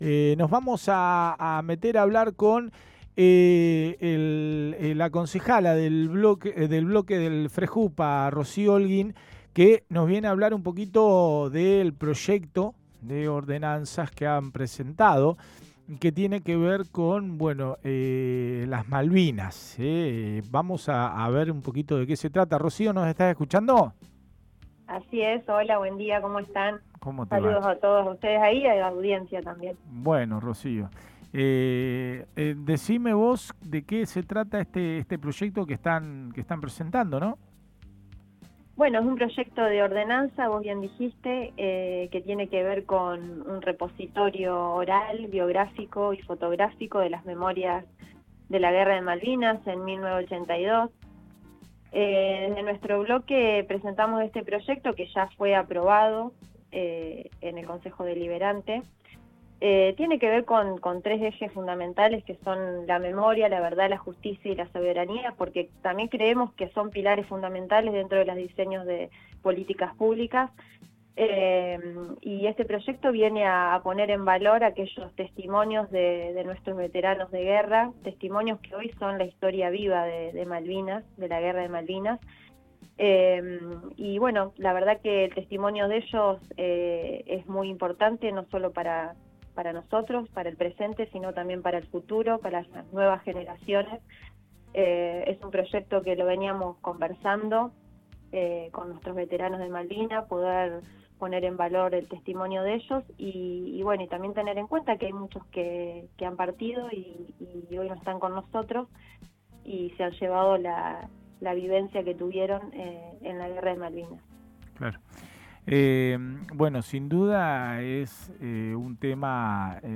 NOTA CON LA CONSEJALA ROCIO OLGUIN
Este lunes, nos acompañó en «el mundo vive equivocado», la Concejala Roció Olguín, quién comenta sobre el proyecto de ordenanza referido al repositorio de la guerra de Malvinas de 1982.